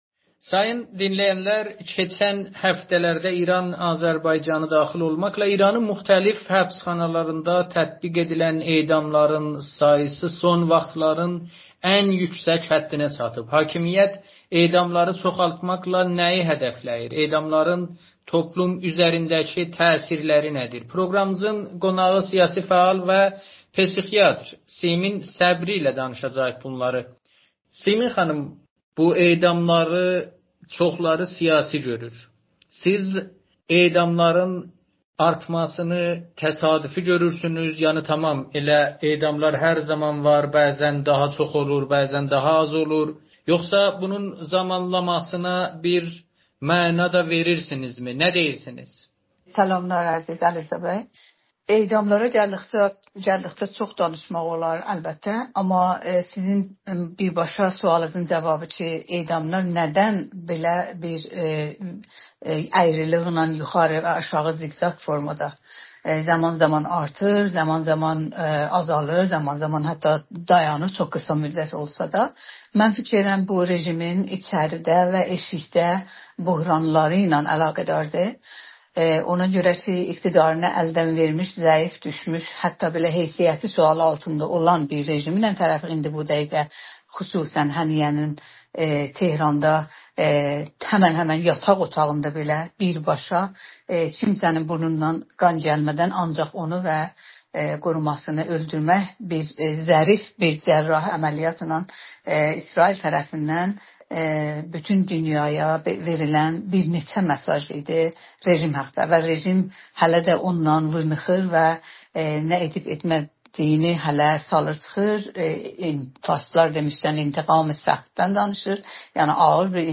Amerikanın Səsi ilə söhbətində